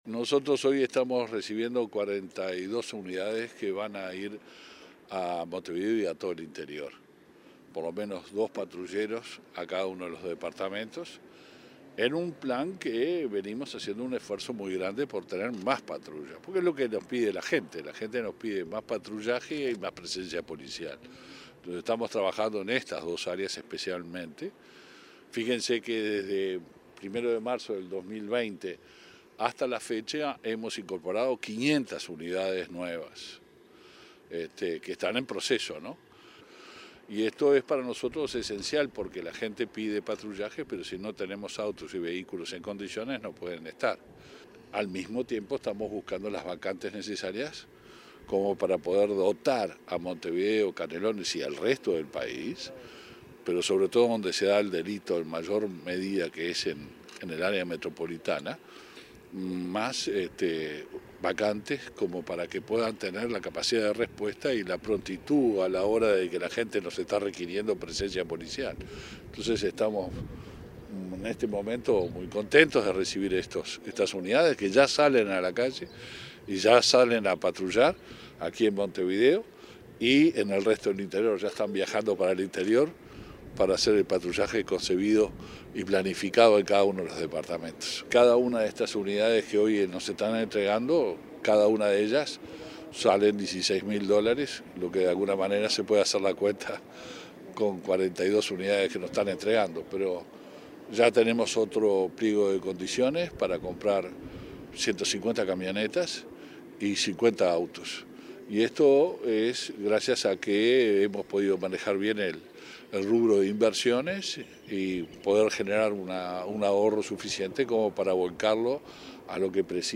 Declaraciones del ministro del Interior, Luis Alberto Heber